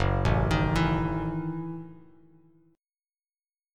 Gbm13 chord